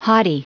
Prononciation du mot haughty en anglais (fichier audio)
Prononciation du mot : haughty